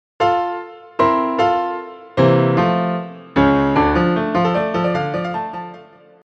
At first we are still happily going along in the key of F. Then out of nowhere these C#’s grab us and pull us into an entirely new key (the key of Dm).
C# is not in the key of F, so it sounds foreign and unexpected but also very exciting.